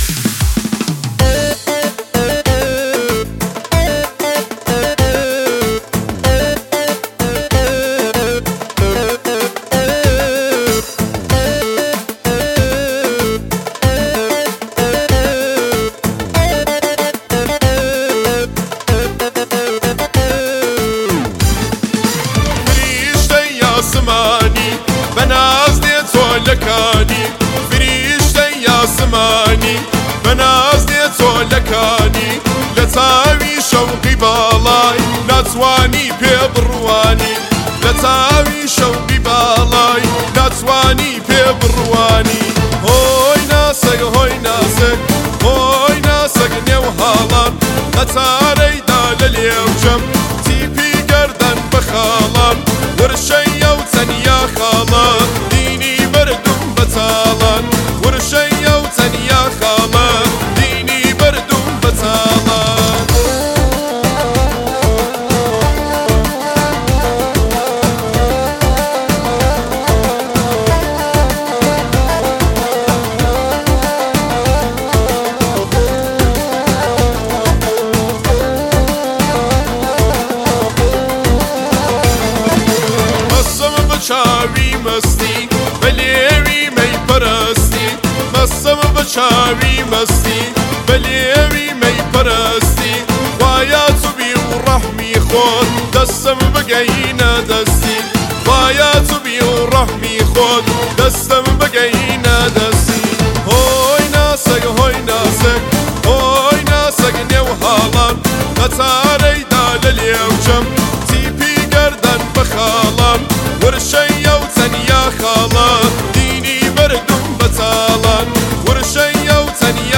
آهنگی احساسی و خاص با ملودی دلنشین
آهنگ کردی عاشقانه